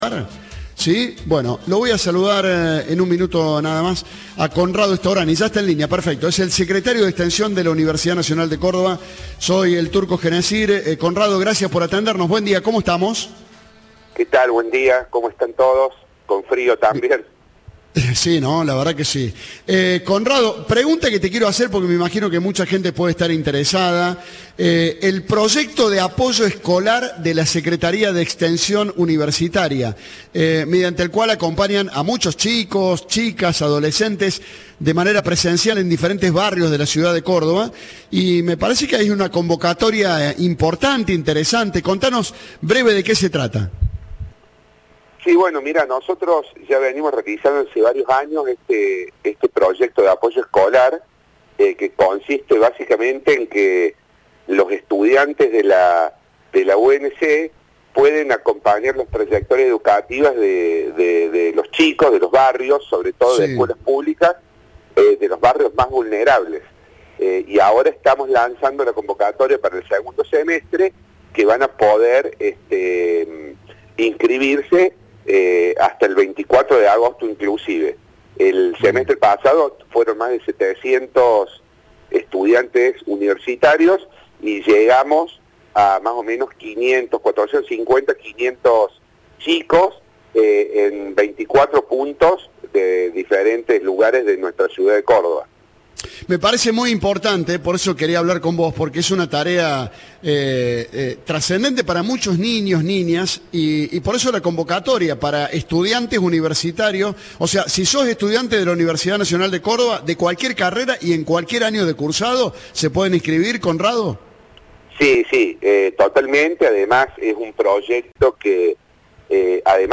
En diálogo con Vamos Viendo por La Popu
Entrevista